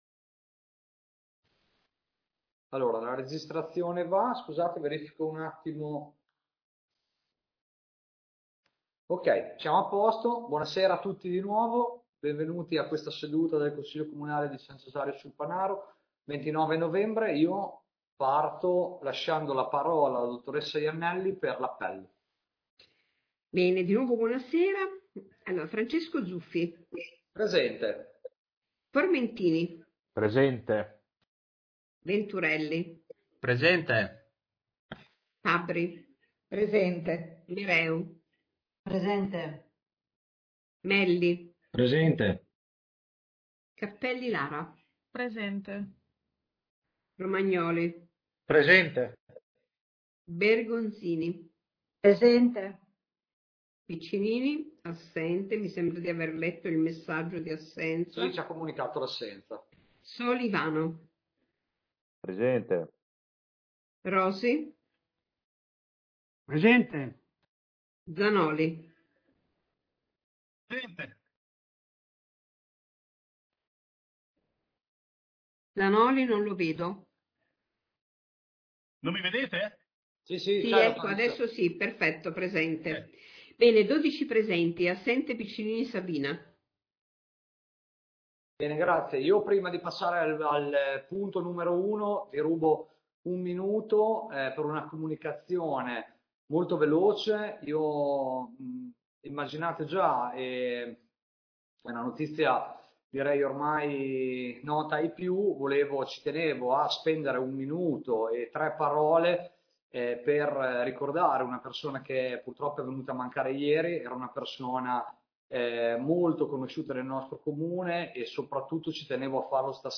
Consiglio Comunale del 29 novembre 2021